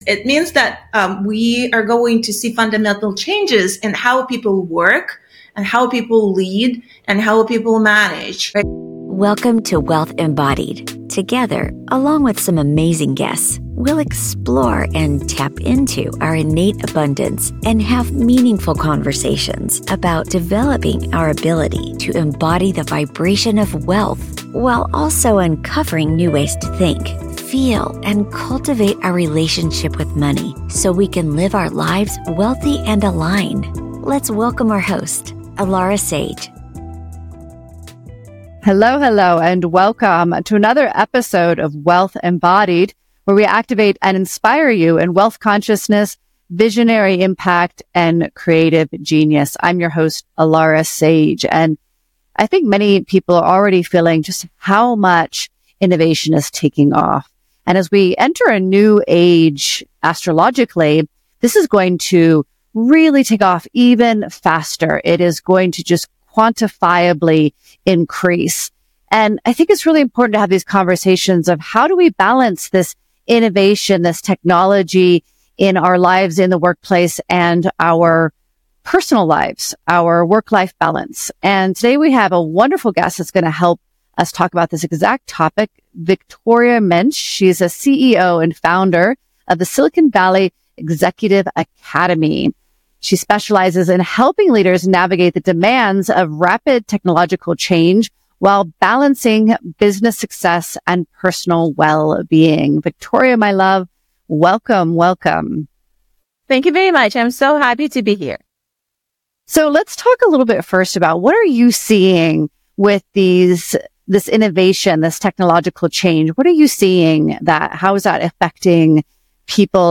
Conversations and guest interviews to help you learn how to activate wealth consciousness, embody the frequency of wealth and apply financial strategies to your life. Listen to compelling conversations and insights on Quantum Mechanics, the Law of Attraction, Manifestation, and Wealth Creation and Management.